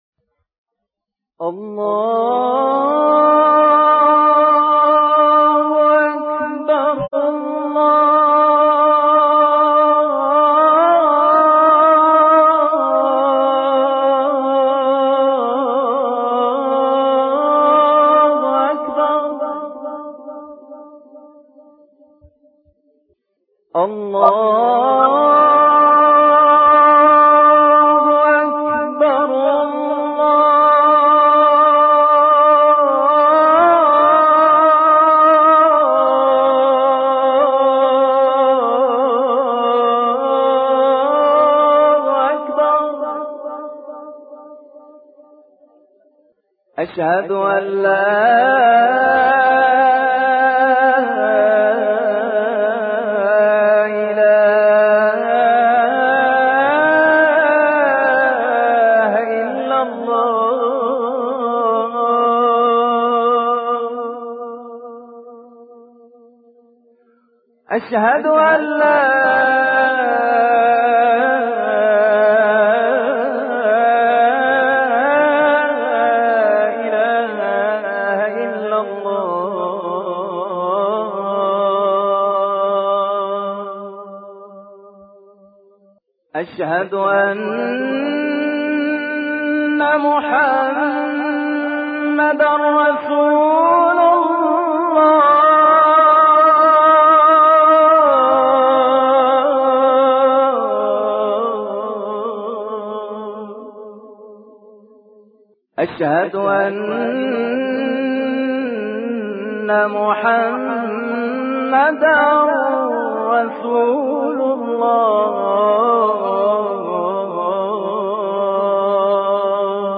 azan-entezar-11.mp3